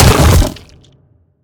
biter-death-big-1.ogg